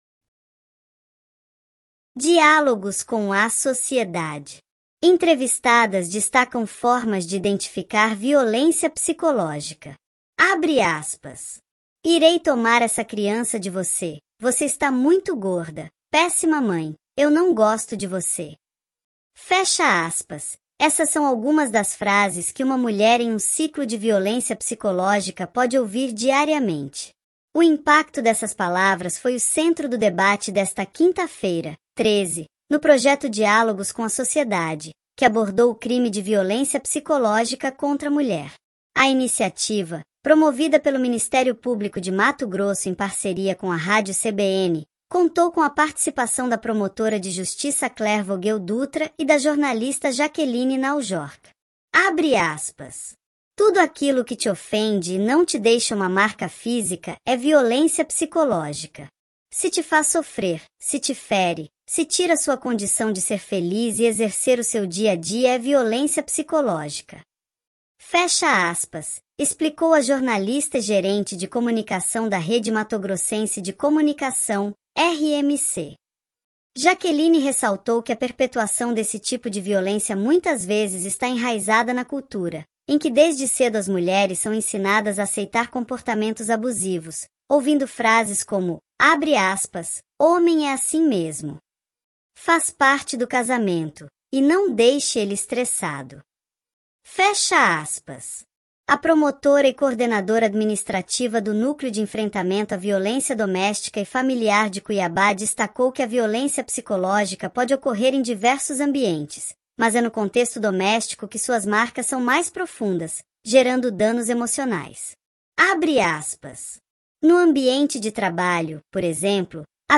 As entrevistas do projeto Diálogos com a Sociedade seguem até o dia 11 de abril, das 14h às 15h, no estúdio de vidro localizado na entrada principal do Pantanal Shopping, com transmissão ao vivo pelo canal do MPMT no YouTube.
Entrevistadas destacam formas de identificar violência psicológica.mp3